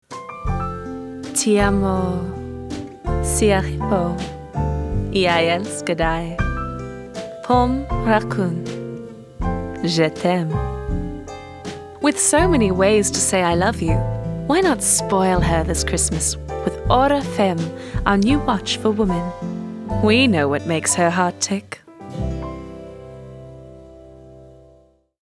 Commercial
Soft/romantic
I communicate with clarity and purpose.